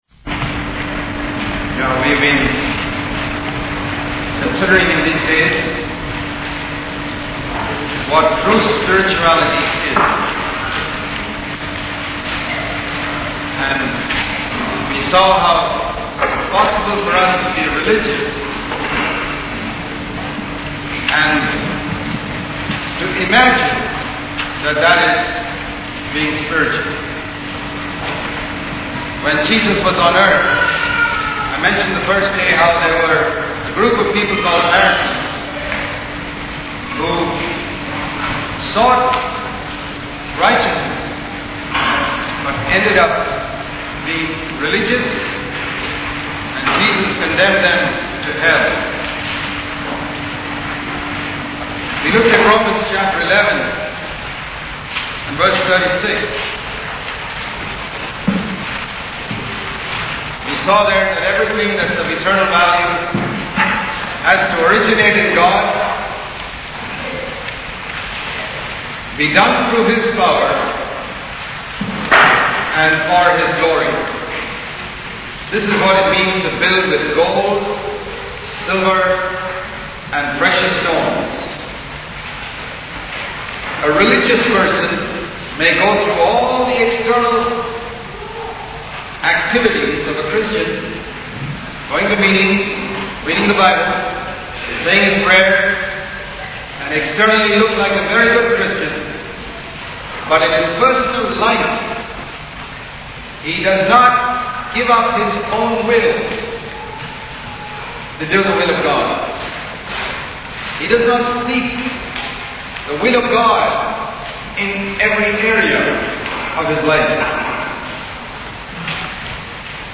In this sermon, the speaker discusses the importance of serving God rather than serving oneself. He highlights the tendency of some individuals, even within the church, to prioritize their own glory rather than glorifying God.